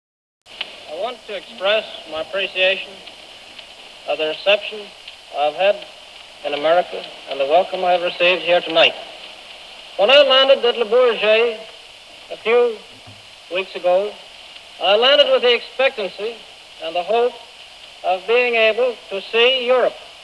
Rede Lindberghs am 11.6.1927 über seinen Transatlantikflug im Mai des gleichen Jahres